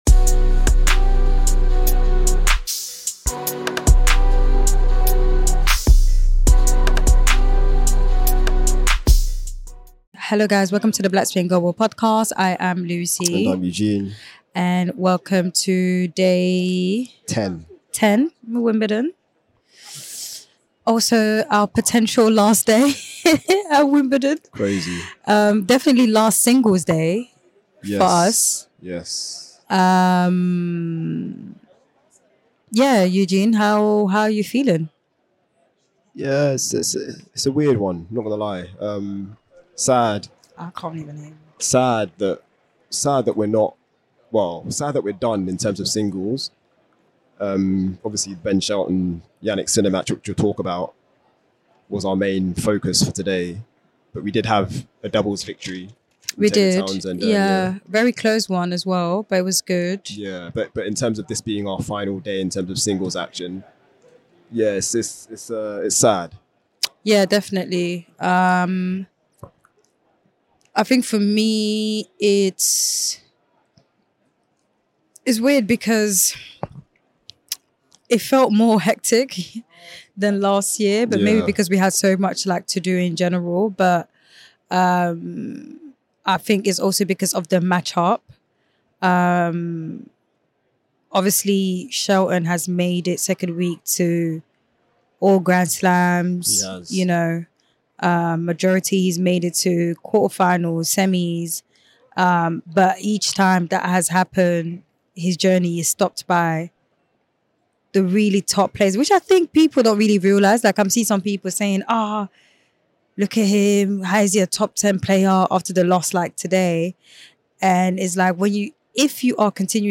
In his post-match press conference, we asked Shelton to reflect on reaching the *quarter-finals or better at all four of the Grand Slams at the age of 22 (Shelton corrected us as he is yet to reach the last eight in Paris).